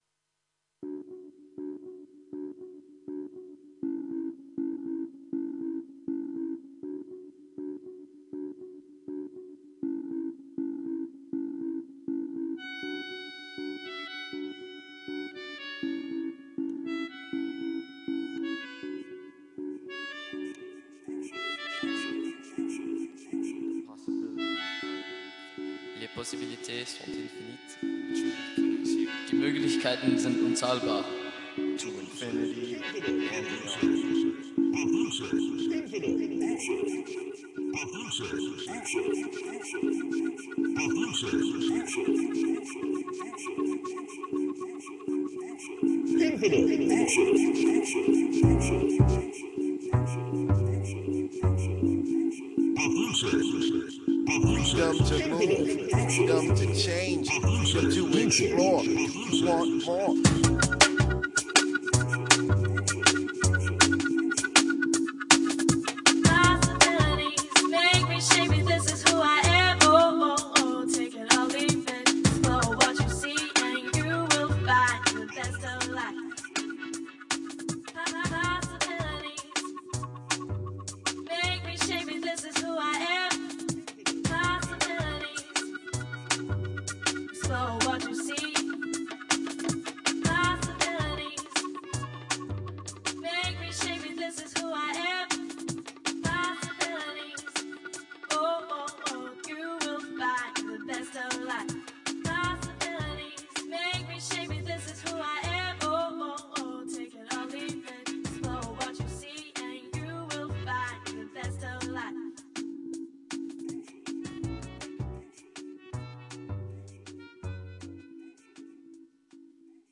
Thursday 31 May 2008, recorded video of the Tech Hui, from the Brierley Theatre, Wellington College.